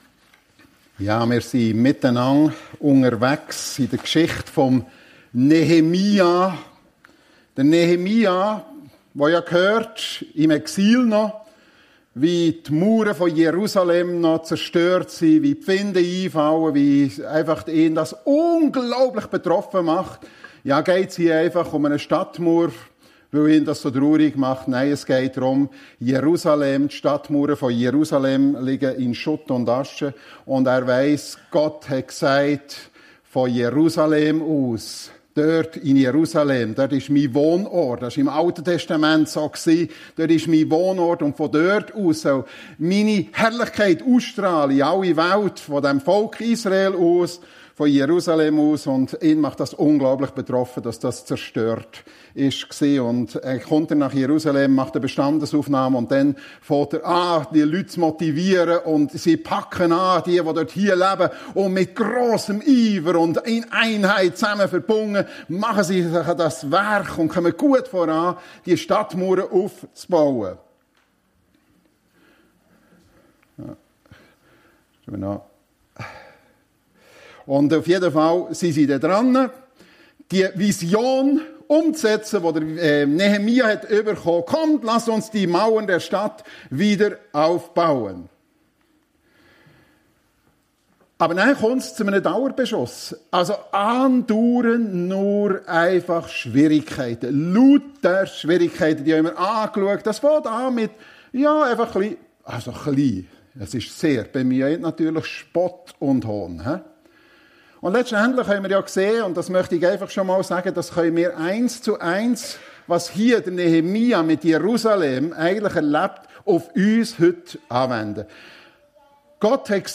Nehemia - Bewegt, wie aus einer Vision Wirklichkeit wurde (Teil 5) ~ FEG Sumiswald - Predigten Podcast